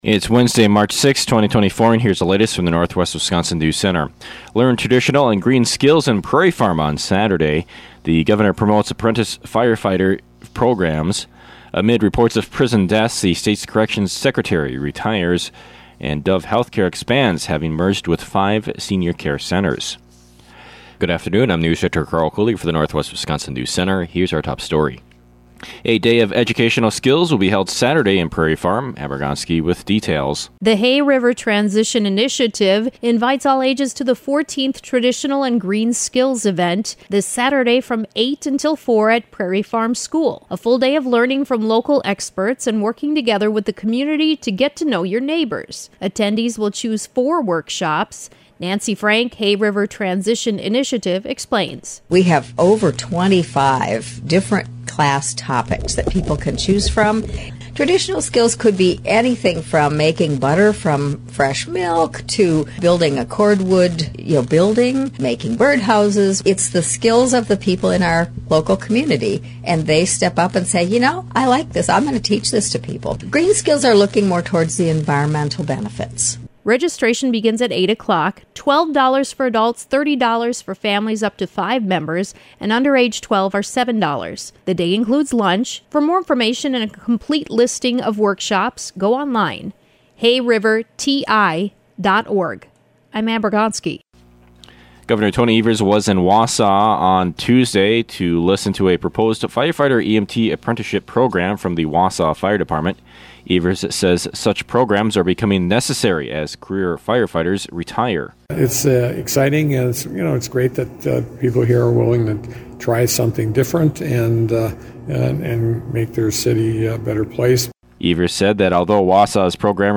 PM NEWSCAST – Wednesday, March 6, 2024 | Northwest Builders, Inc.